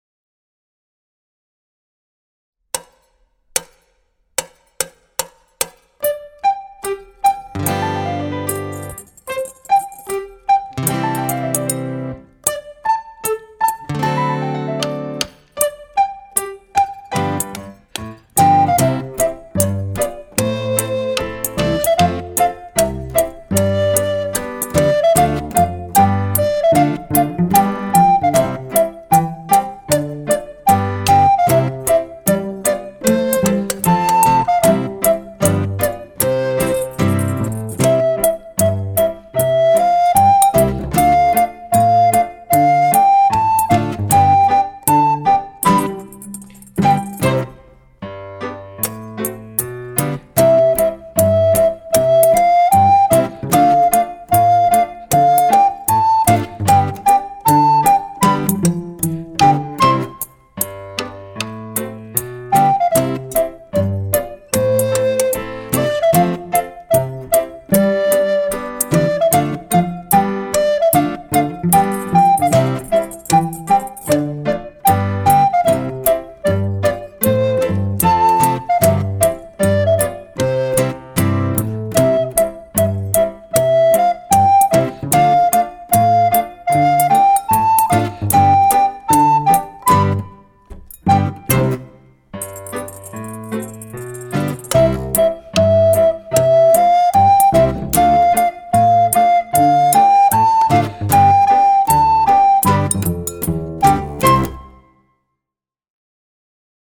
für Altblockflöte und Klavier